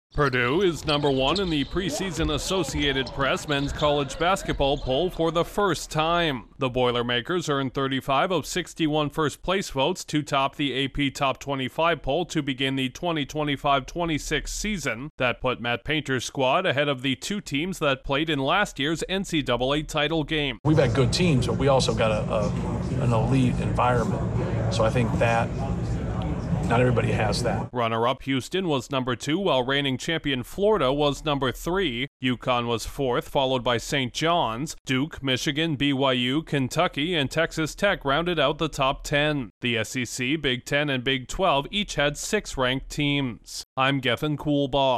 A Big Ten contender begins this season on top of the mountain in college basketball. Correspondent